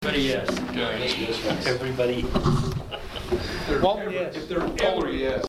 March 4, 2024 Town Council Meeting